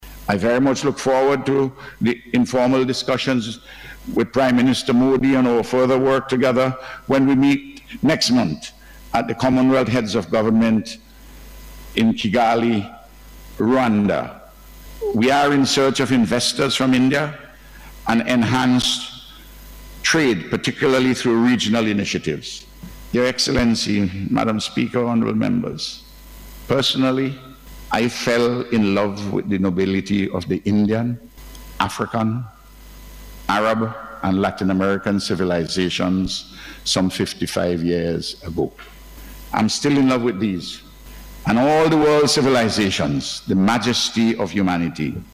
The Prime Minister made the announcement during a Special Sitting of Parliament held last week, as part of the programme for the officials visit to the state of the President of India, His Excellency Shri Ram Nath Kovind.